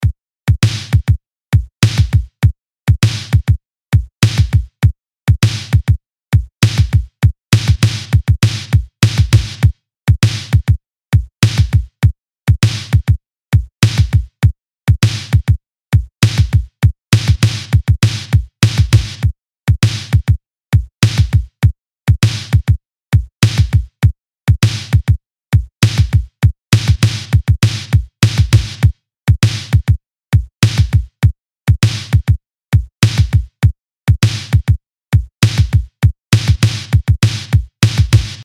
LP 123 – DRUM LOOP – RETRO – 100BPM